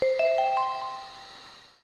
Sound effect that plays when a control tutorial appears on the television screen in Game & Wario